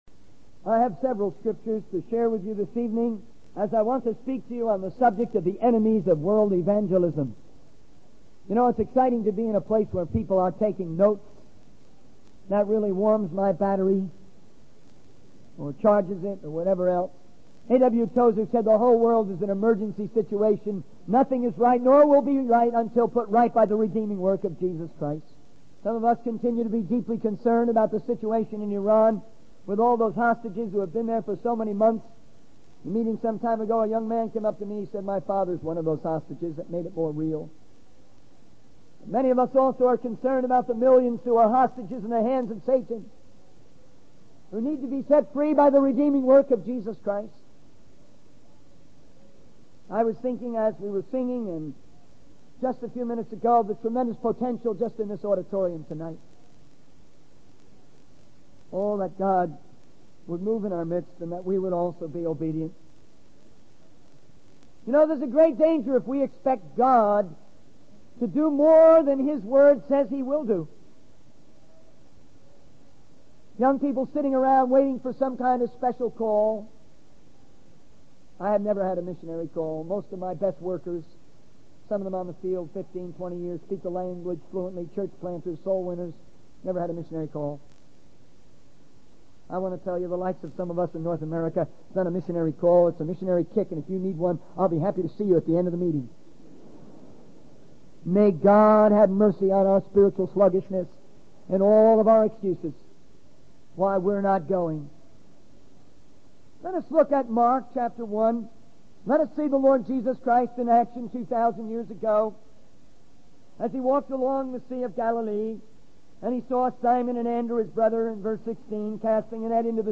In this sermon, the speaker emphasizes the importance of taking action and not making excuses when it comes to spreading the word of God.